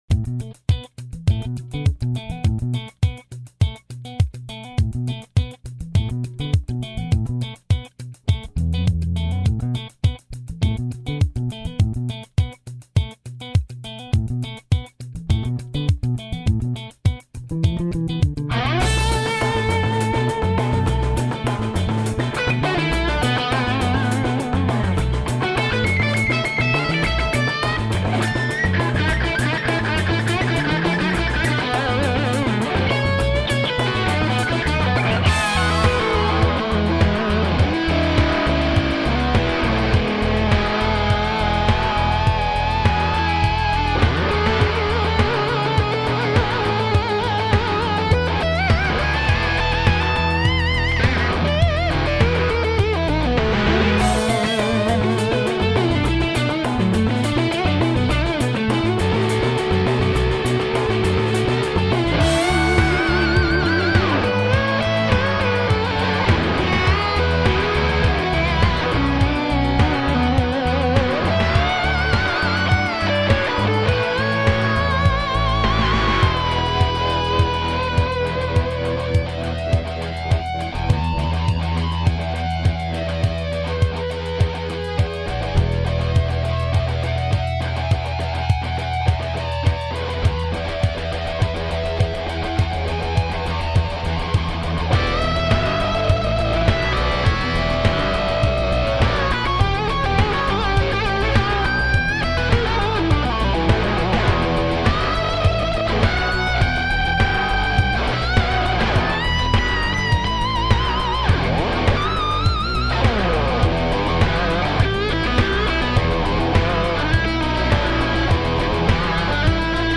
All tube , 3 channel preamp